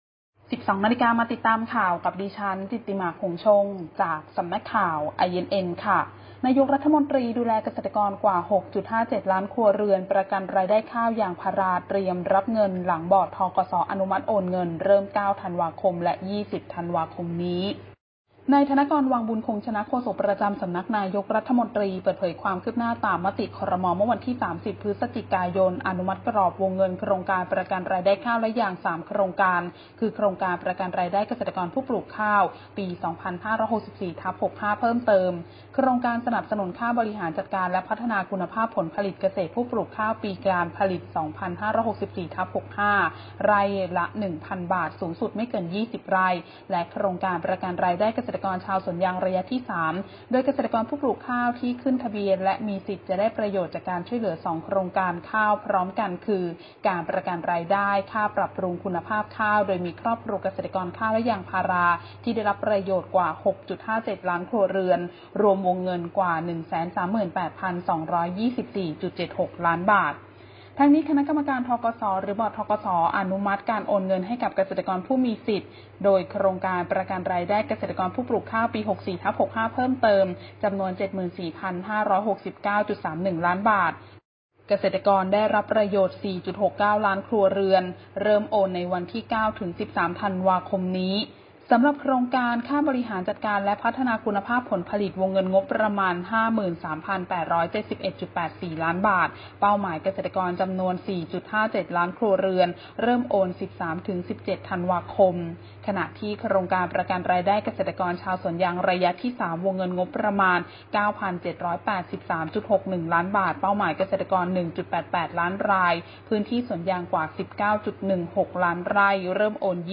คลิปข่าวต้นชั่วโมง
ข่าวต้นชั่วโมง 12.00 น.